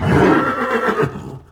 combat / creatures / horse / he / attack3.wav
attack3.wav